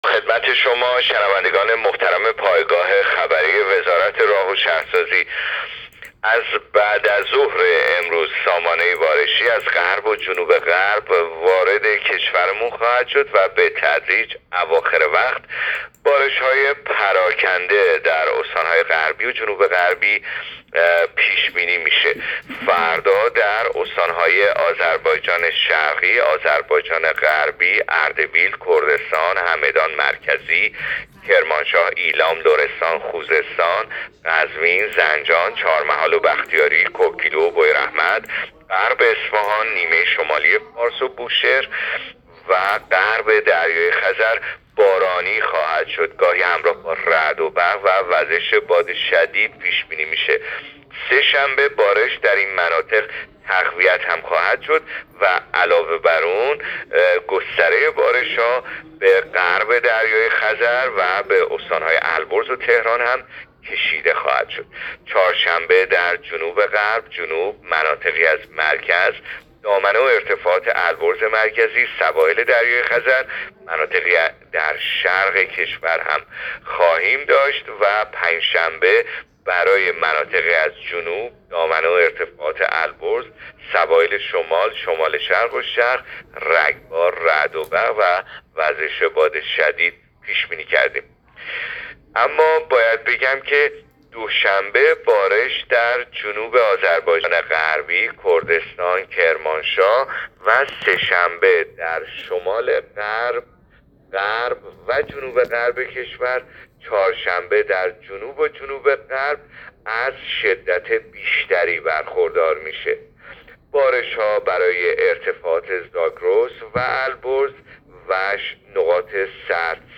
گزارش رادیو اینترنتی پایگاه‌ خبری از آخرین وضعیت آب‌وهوای ۴ آذر؛